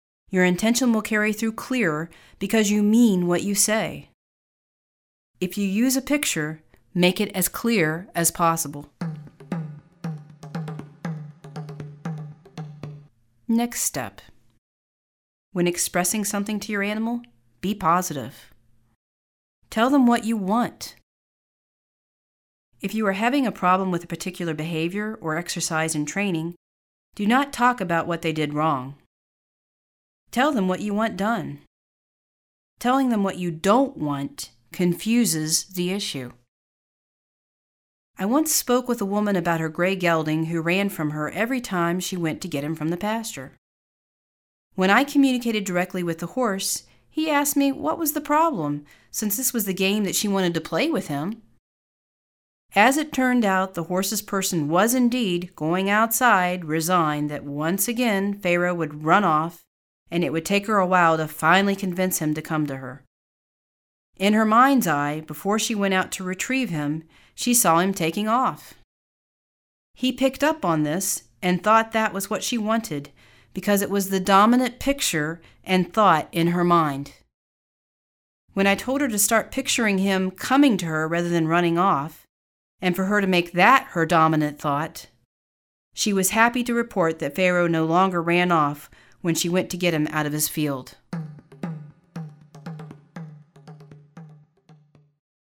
Naučte se komunikovat se svými zvířecími přáteli v tomto verbálně vedeném cvičení Hemi-Sync®.